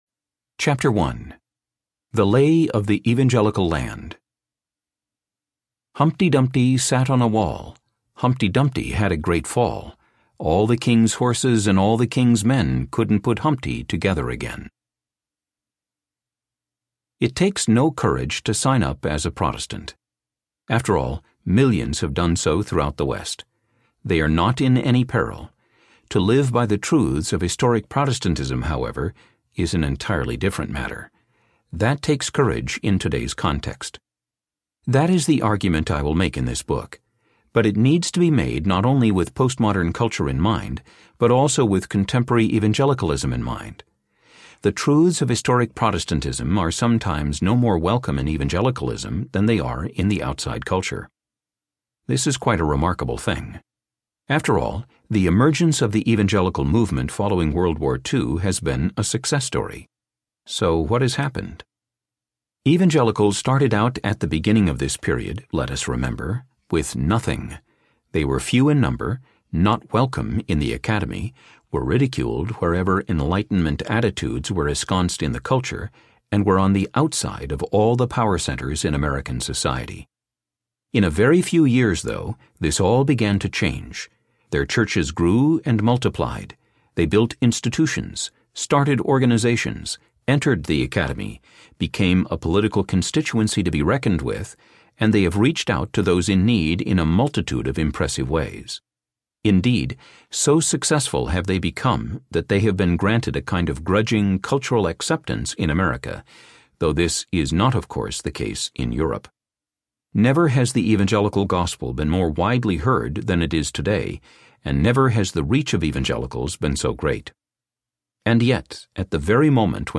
The Courage to Be Protestant Audiobook
Narrator
9.8 Hrs. – Unabridged